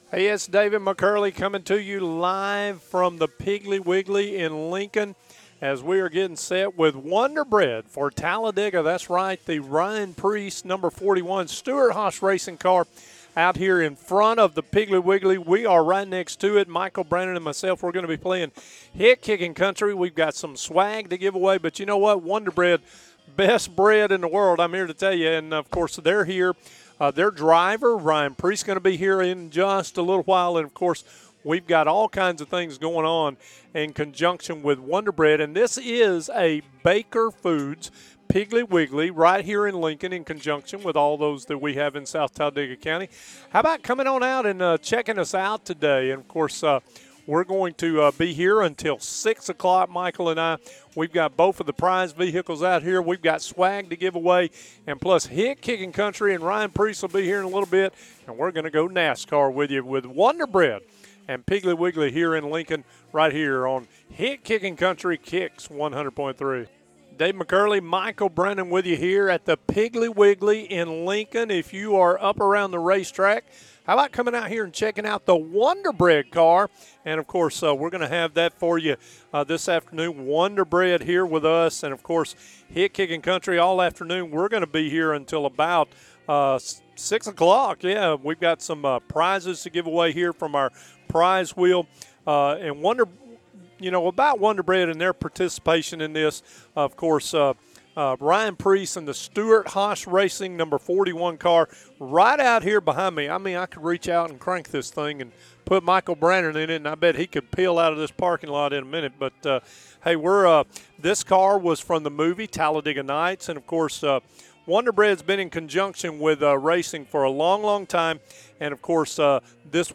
Live from the Piggly Wiggly in Lincoln